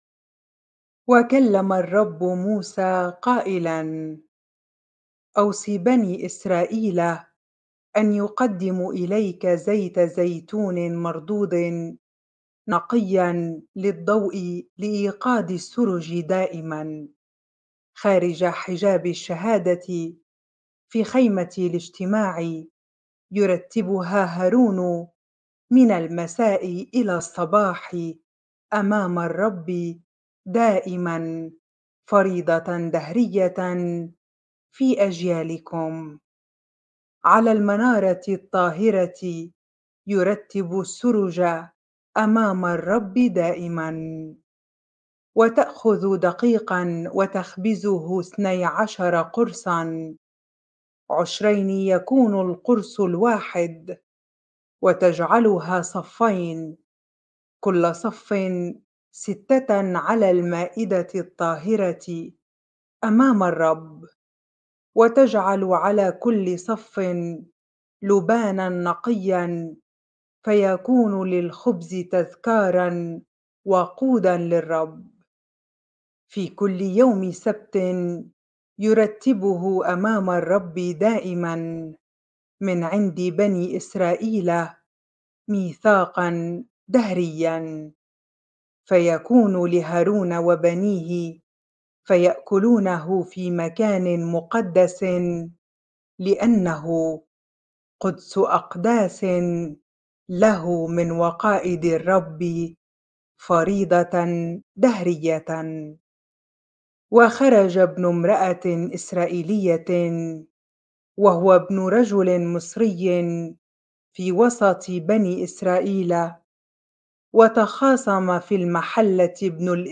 bible-reading-leviticus 24 ar